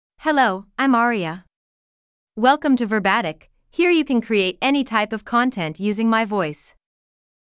AriaFemale English AI voice
Aria is a female AI voice for English (United States).
Voice sample
Female
Aria delivers clear pronunciation with authentic United States English intonation, making your content sound professionally produced.